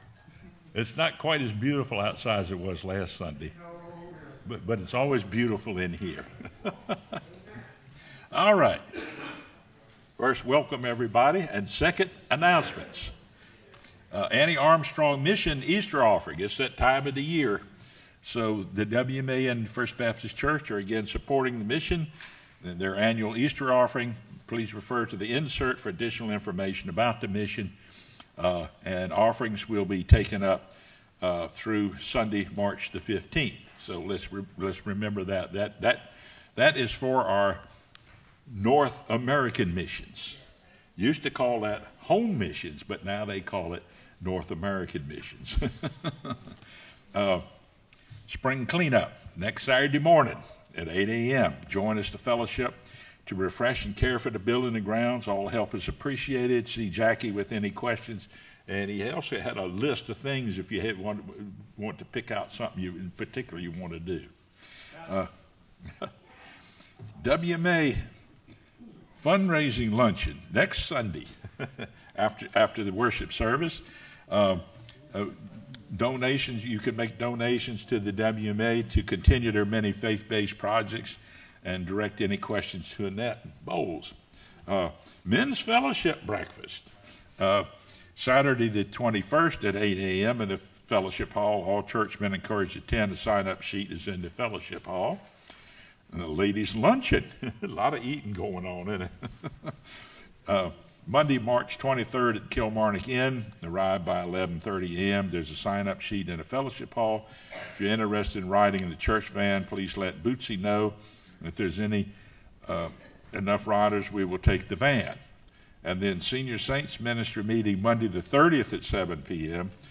sermonMar08-CD.mp3